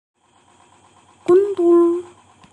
Darmowe dzwonki - kategoria SMS
Dzwonek - Bul bul
Cichy, miły dla ucha dzwięk przypominający bulgotanie wody.
bul-bul.mp3